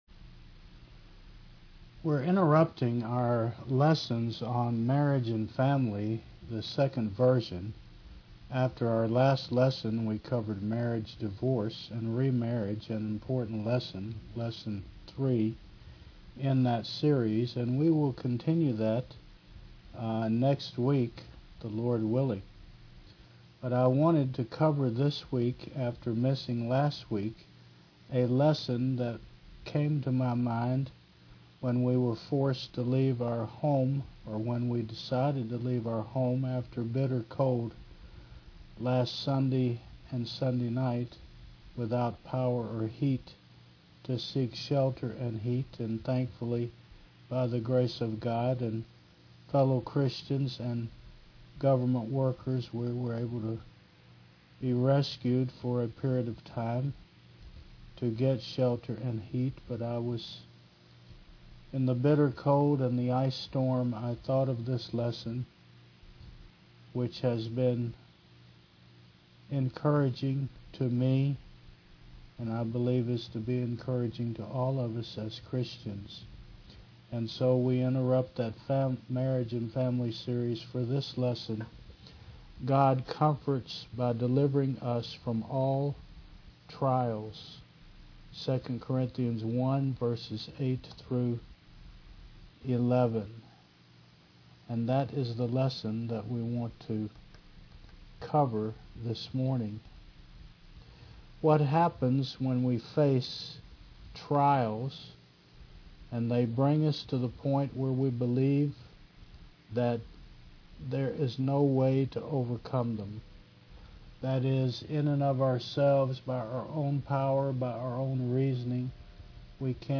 Service Type: Mon. 9 AM